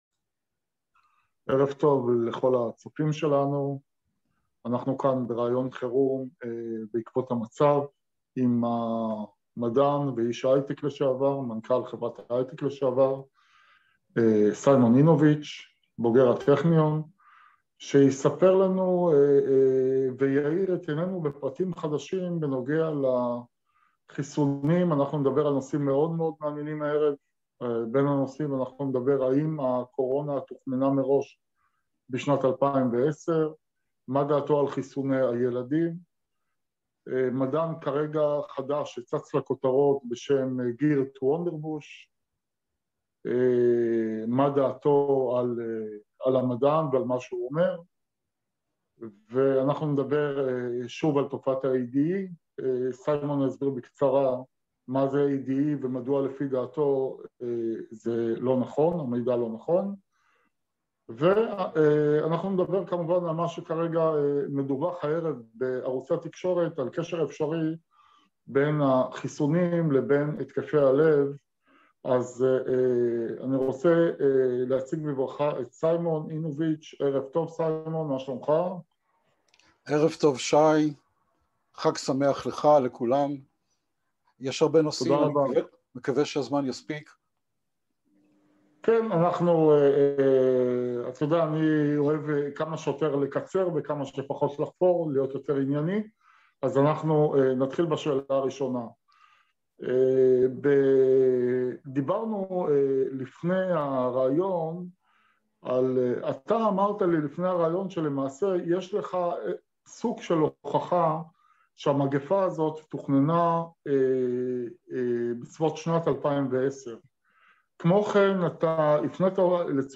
ראיון חירום